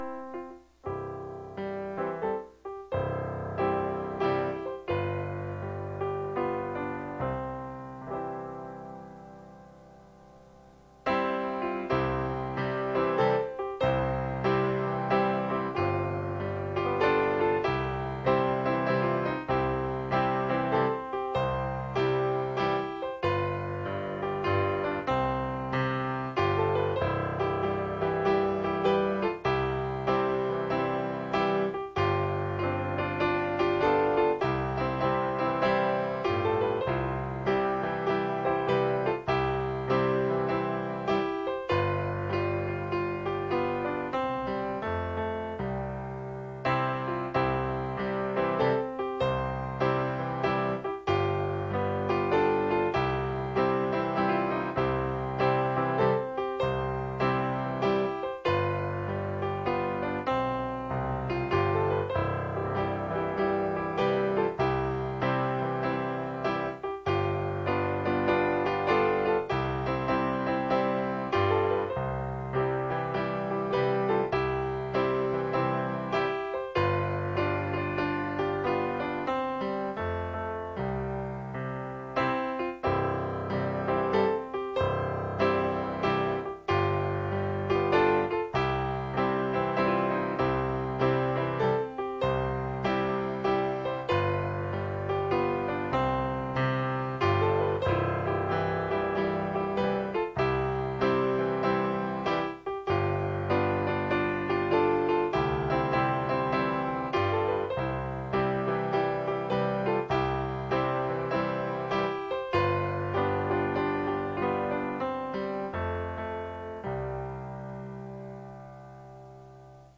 Bible Text: Exodus 3:13-16 | Preacher